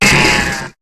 Cri de Kranidos dans Pokémon HOME.